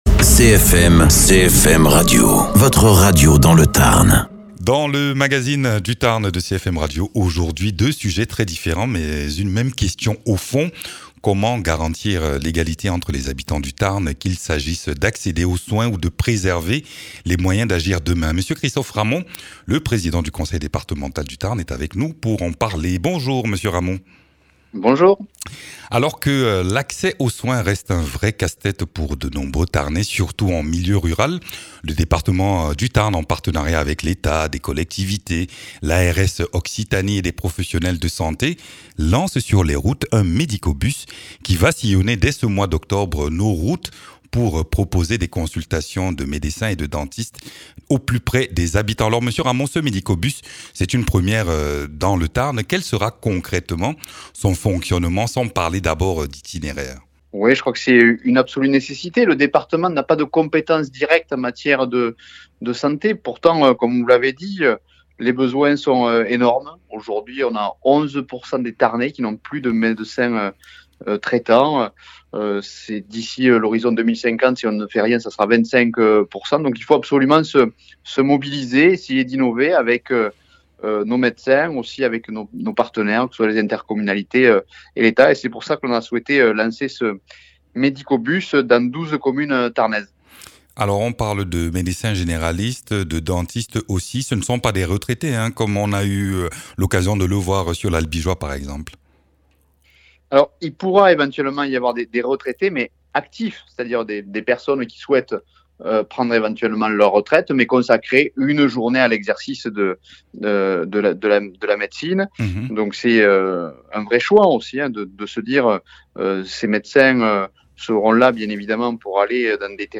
Interviews
Invité(s) : M. Christophe Ramond, Président du Département du Tarn.